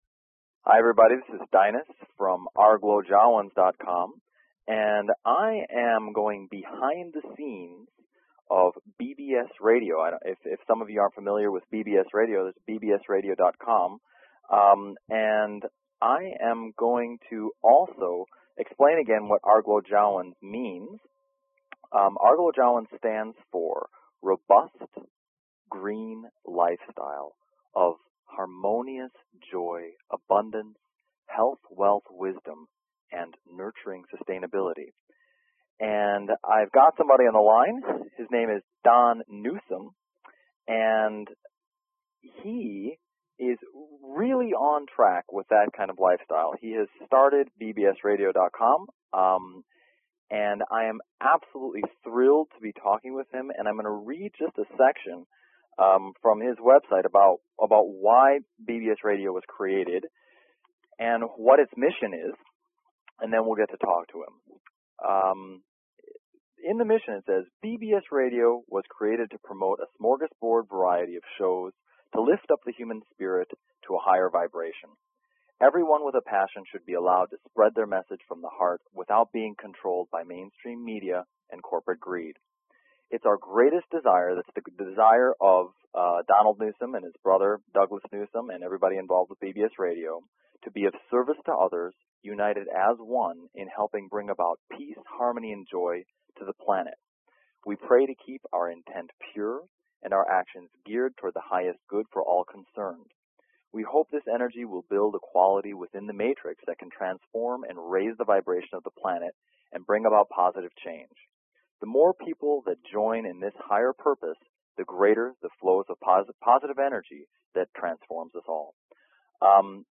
Talk Show Episode
Drive time radio with a metaphysical slant.